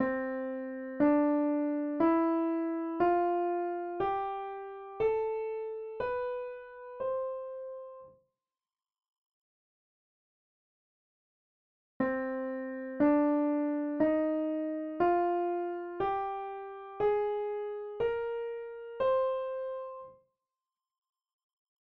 In the example that follows, you probably will be able to hear the difference in the quality between the C major and C minor scale even though they start on the same pitch:
Scale examples – major and minor